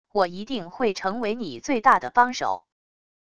我一定会成为你最大的帮手wav音频生成系统WAV Audio Player